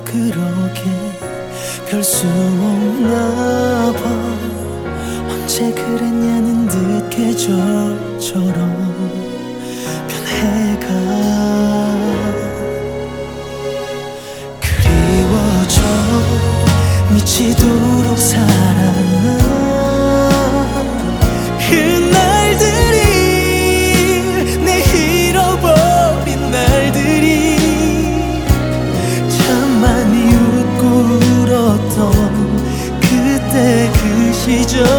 Жанр: Поп / K-pop